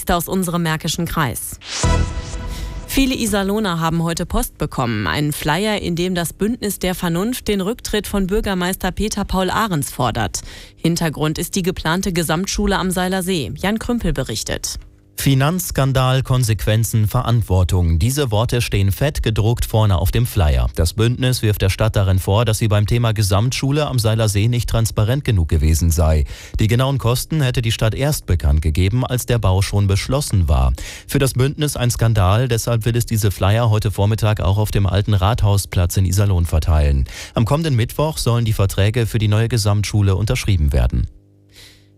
Unser Lokalradio war natürlich gleich am 10.06.2017 vor Ort und hat schon bei der Verteilaktion O-Töne gesammelt und über uns berichtet!